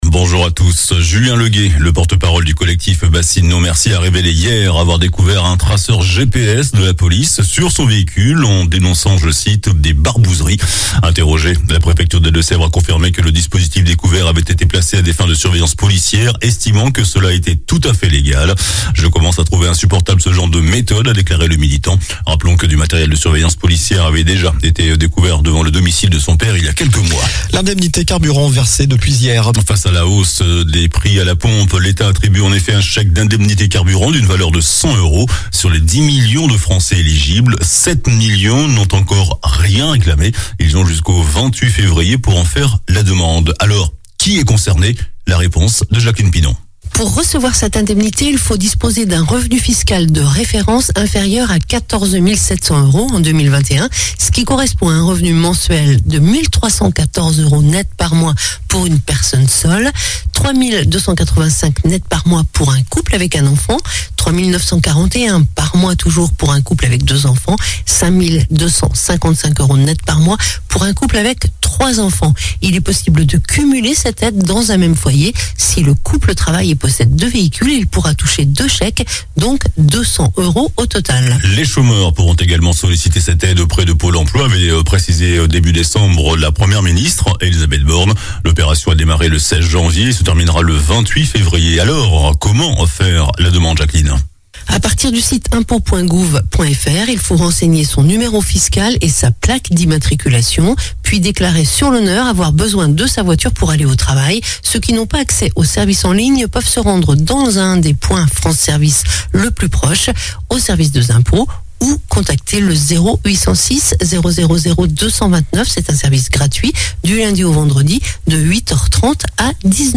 JOURNAL DU SAMEDI 28 JANVIER